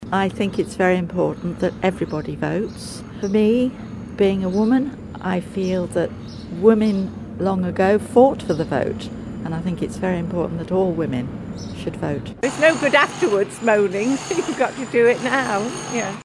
These residents in Ramsey are calling on those who haven't registered yet to do so: Listen to this audio